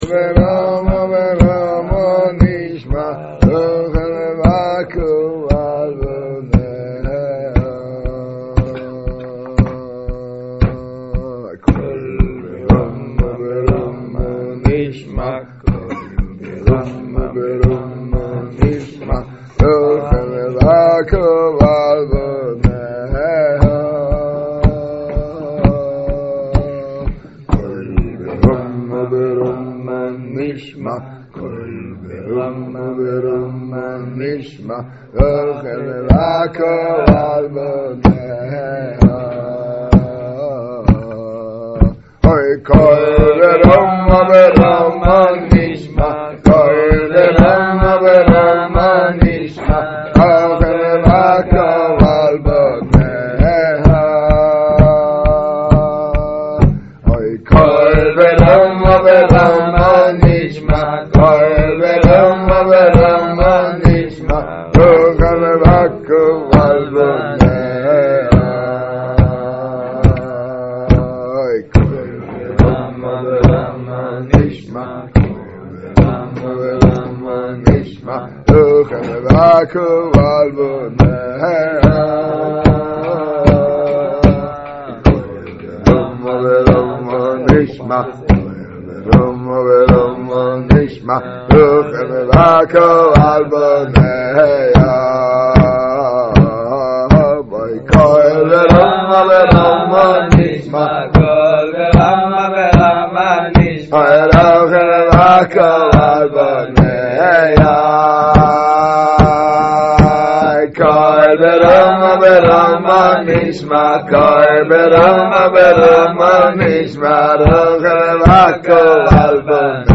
השיעור באור עציון תשעג.
קטגוריה: שיעור, שיעור באור עציון, תוכןתג: מעשה ב7 קבצנים, סיפורי מעשיות